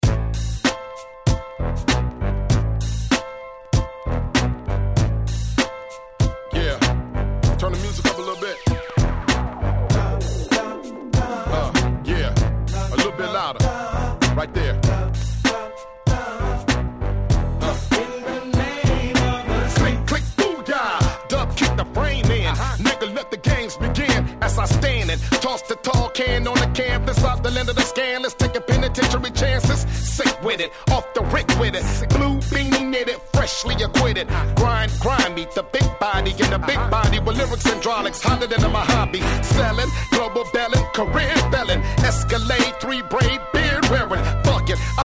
02' Super Hit West Coast Hip Hop !!